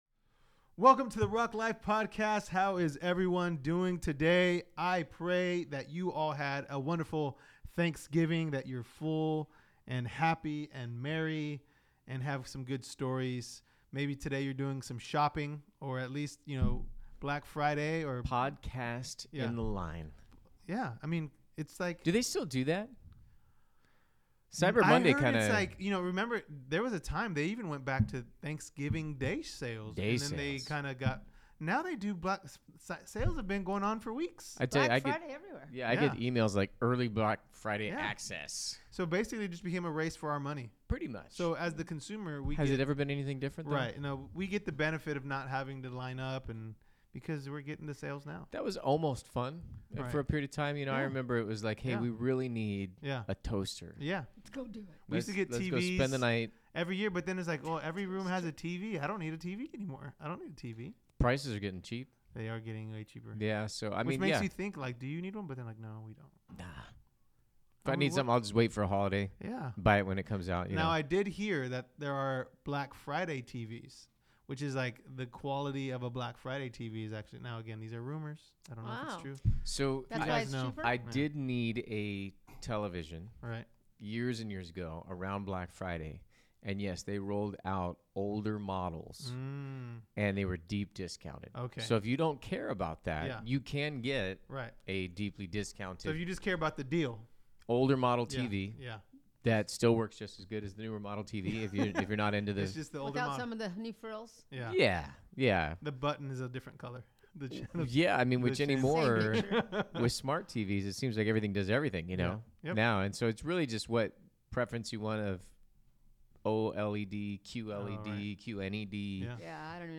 Rock Life Podcast: Episode 88 | Pastors Q&A Panel Part 2 | The Rock Church